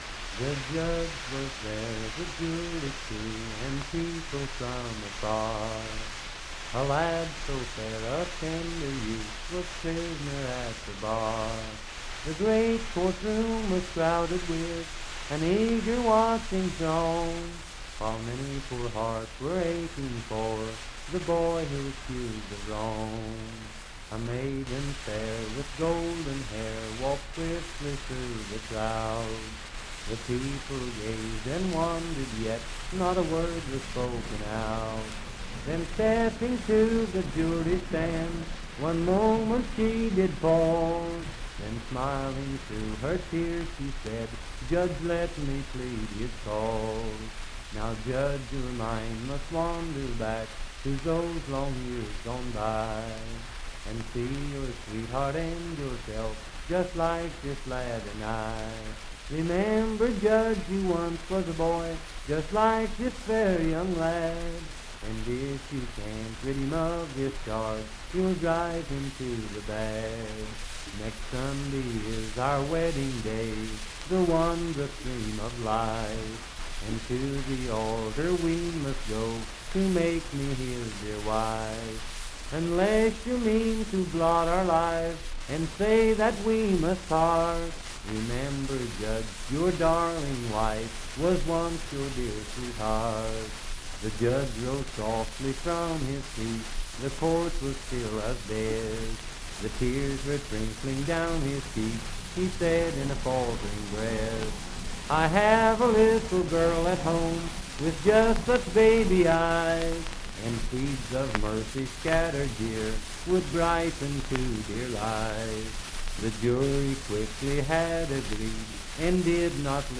Unaccompanied vocal music
Voice (sung)
Parkersburg (W. Va.), Wood County (W. Va.)